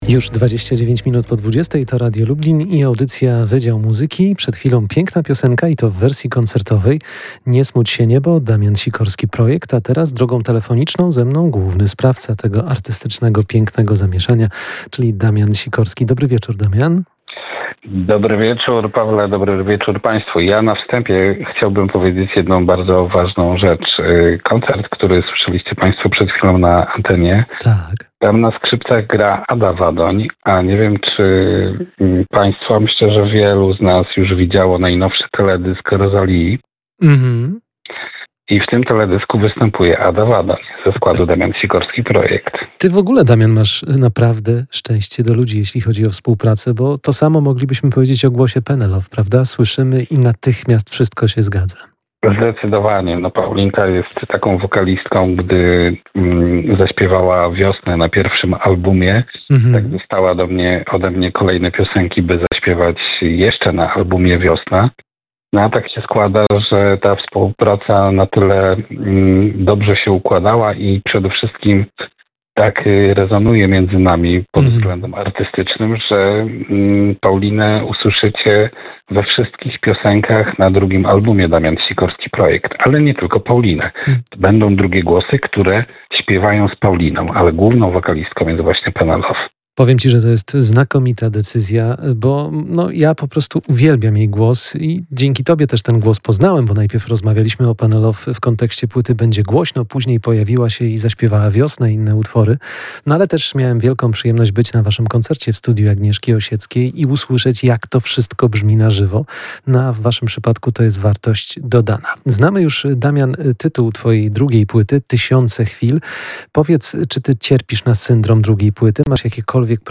muzyk, multiinstrumentalista i autor piosenek był gościem „Wydziału Muzyki”.